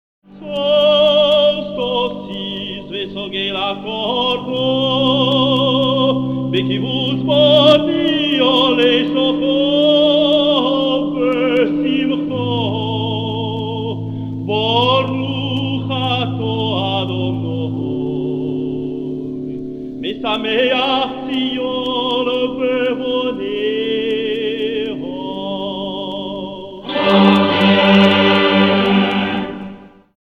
5e bénédiction (rite ashkenaze
accompagné à l’orgue